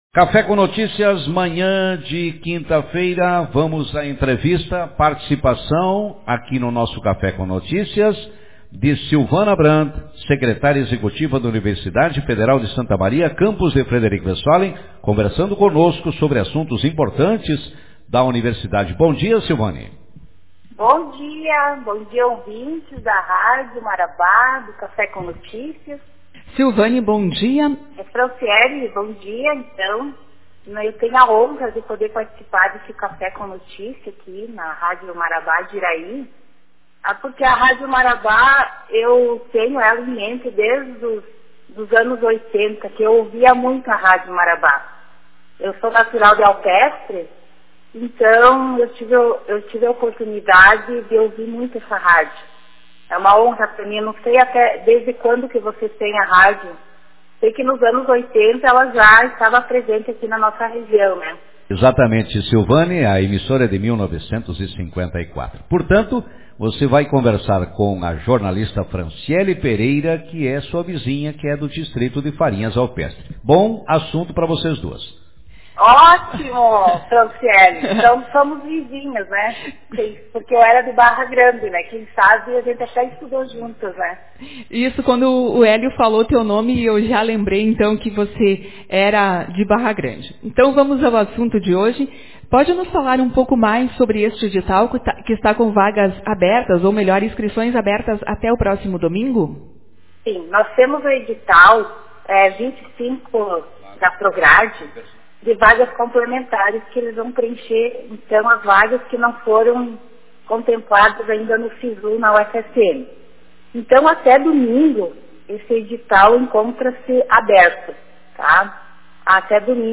Manchete